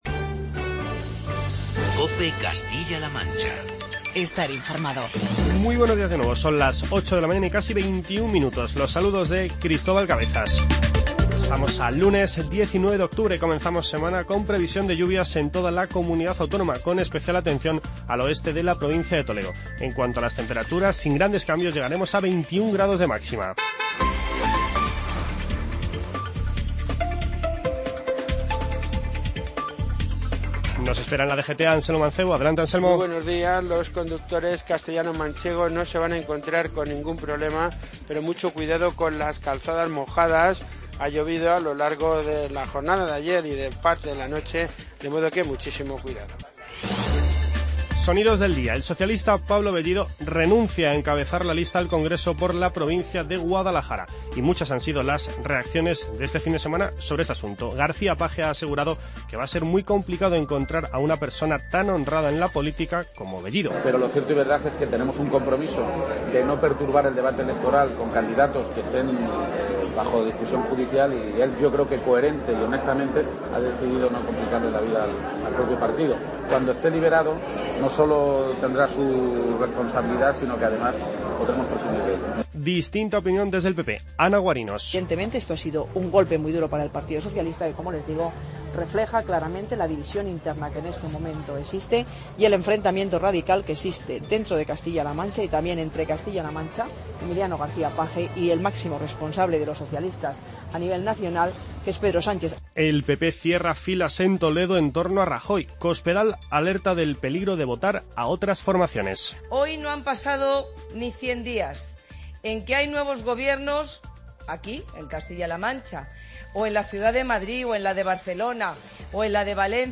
Escucha los principales sonidos de las últimas horas.
Hoy destacamos las palabras de García-Page, Guarinos, Cospedal y Rajoy